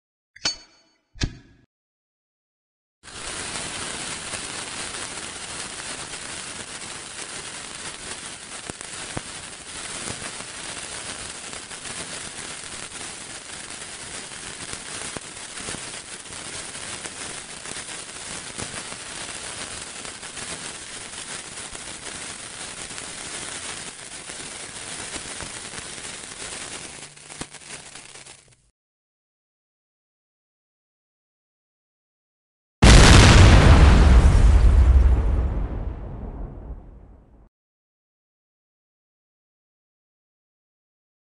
Tiếng Bom Nổ và tiếng Dây cháy chậm cháy
Hiệu Ứng Âm Thanh: Tiếng Bom Nổ & Dây Cháy Chậm
• Tiếng Dây Cháy Chậm (Fuse Burning): Mang âm thanh xì xào, lách tách của lửa đốt cháy thuốc súng. Nhịp điệu dồn dập của tiếng cháy tạo ra áp lực thời gian, khiến không gian trở nên căng thẳng.
• Tiếng Bom Nổ (Bomb Blast): Một cú va chạm âm thanh cực đại với dải âm siêu trầm. Tiếng nổ này thường có độ vang xa, mô phỏng một vụ nổ có sức công phá lớn trên diện rộng.
• Sự kết hợp hoàn mỹ: Sự tương phản giữa tiếng xì xào nhỏ bé của dây cháy và cú nổ sấm sét ngay sau đó tạo nên một hiệu ứng thính giác cực kỳ ấn tượng.